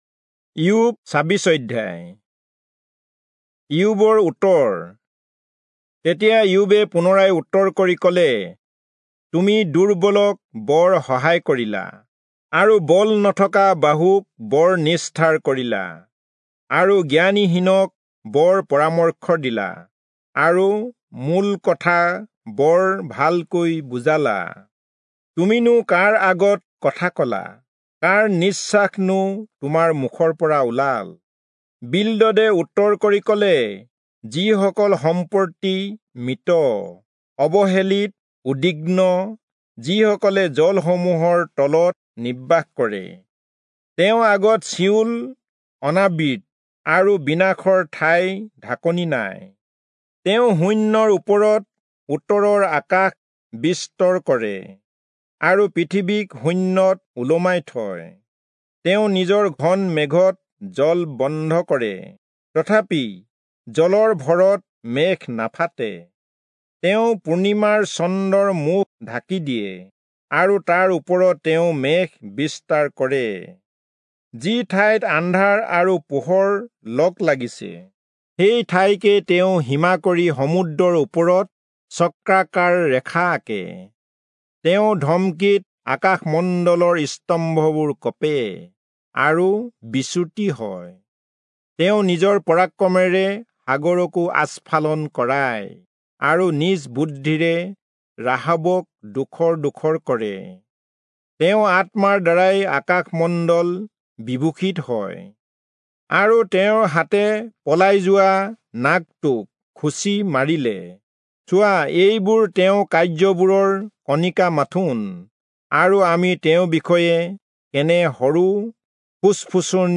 Assamese Audio Bible - Job 16 in Mhb bible version